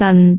speech
cantonese
syllable
pronunciation
dan4.wav